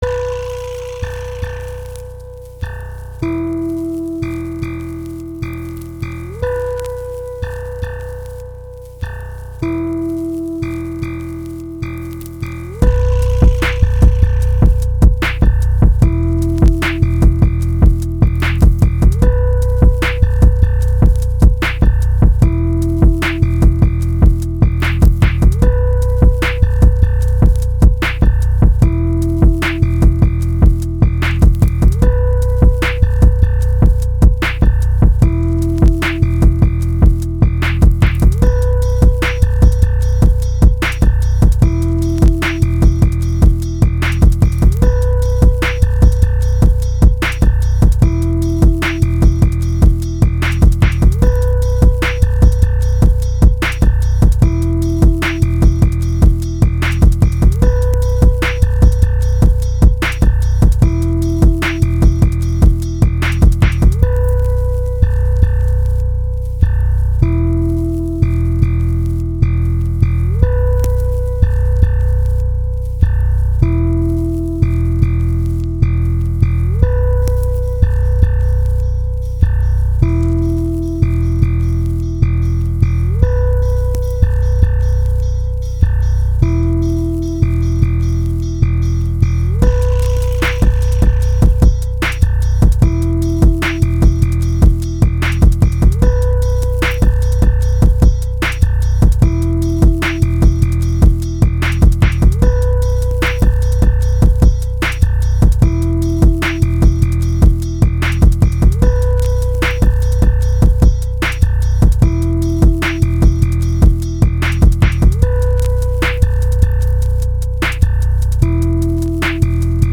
kinda random beat i made. it gives me an ominous analog horror feeling but that’s just me
memphis typebeat
Phonk